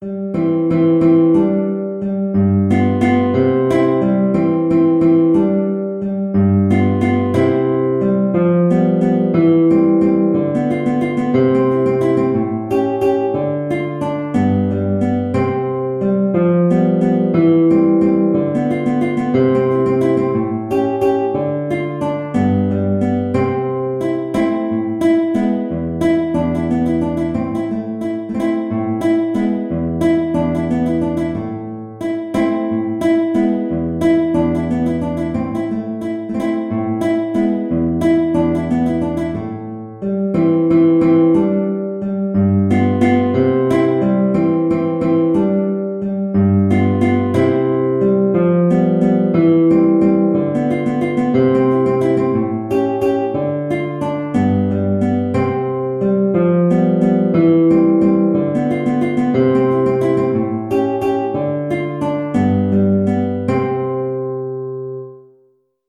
Guitar version
3/8 (View more 3/8 Music)
Classical (View more Classical Guitar Music)